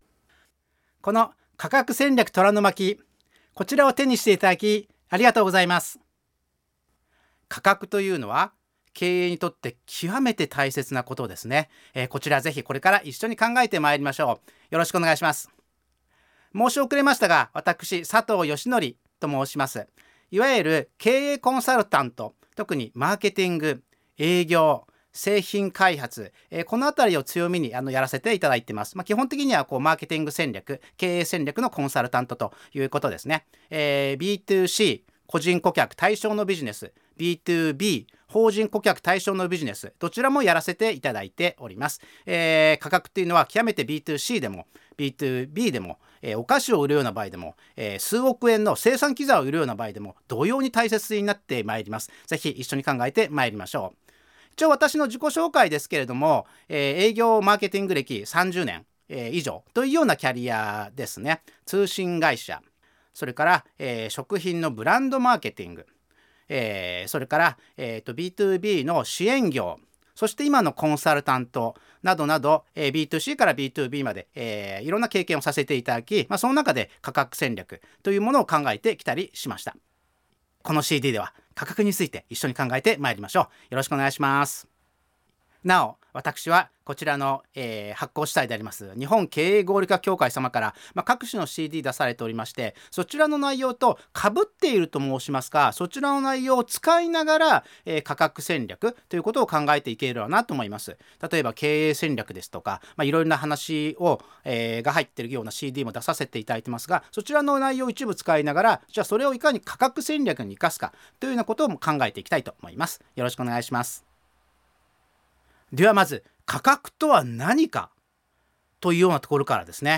コストアップが続く経営環境下での価格戦略 講話ＣＤ版・デジタル版